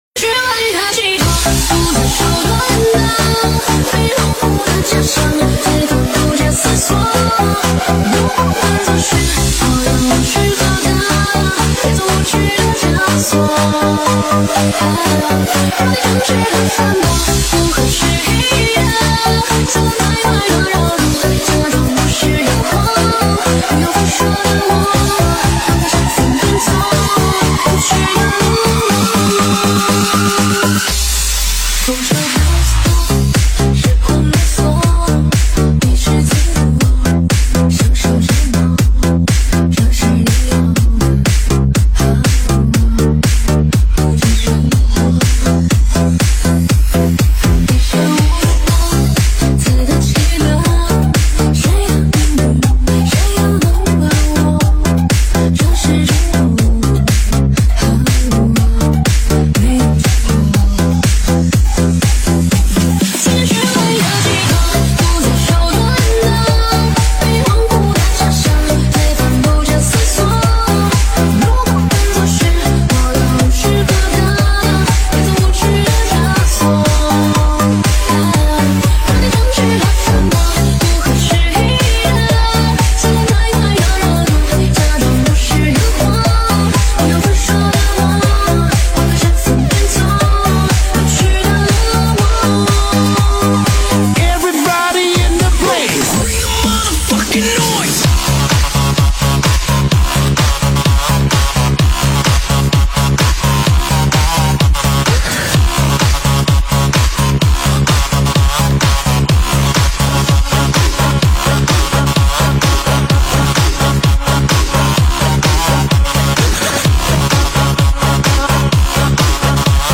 本铃声大小为991.3KB，总时长140秒，属于DJ分类。